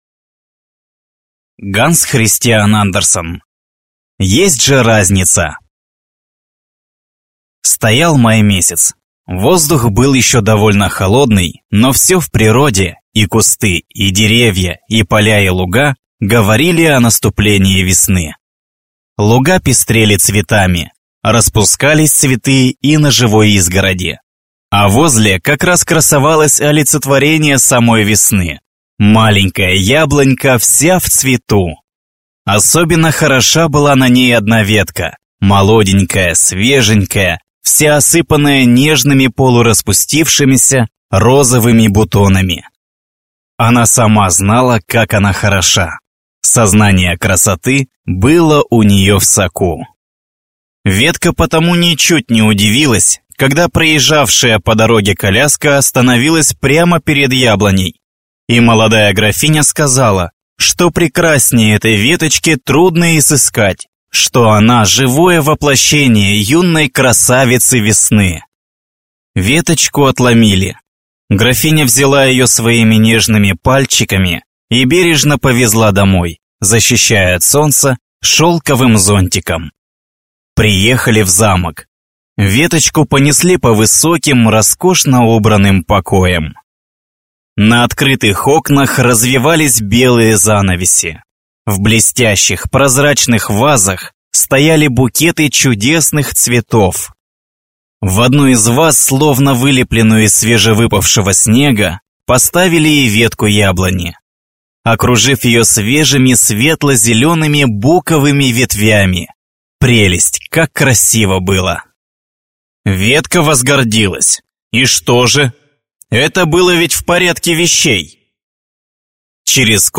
Аудиокнига «Есть же разница!»